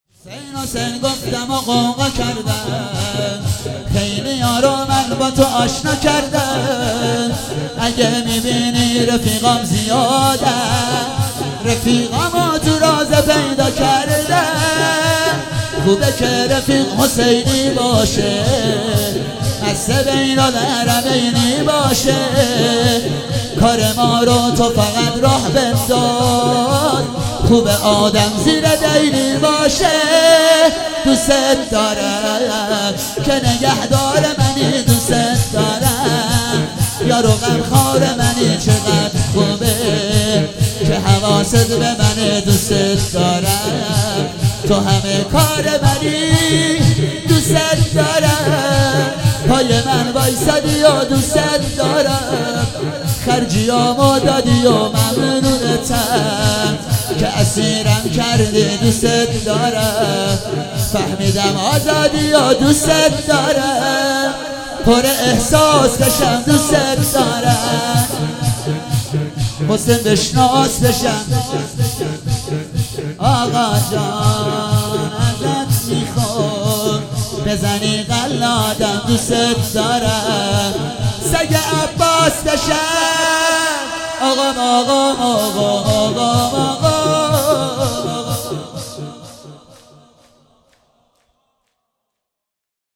هفتگی 7 تیر 97 - شور - حسین حسین گفتمو غوغا کردم